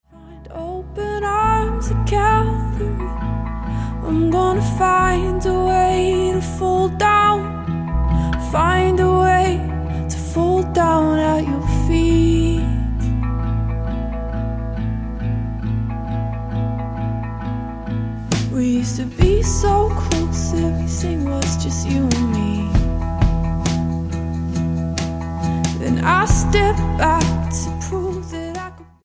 STYLE: Pop
brittle and vulnerable yet suffused with hope